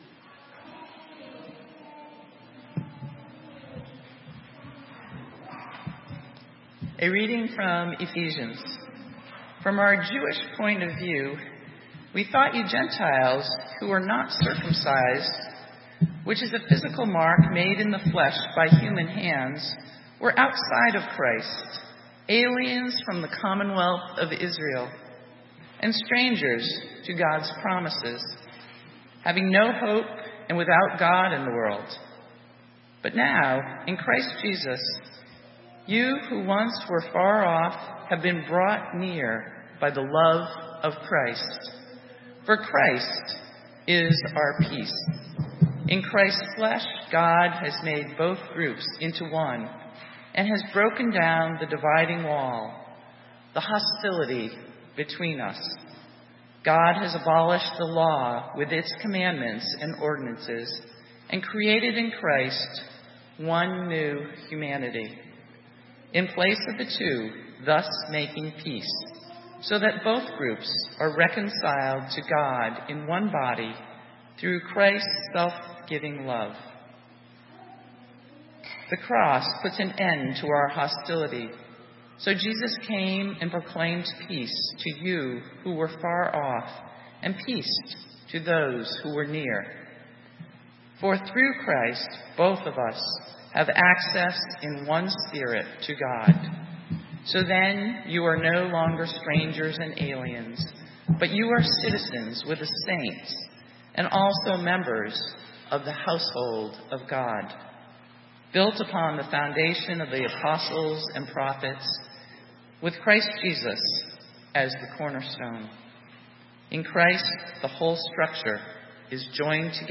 Sermon:Called to heal - St. Matthew's UMC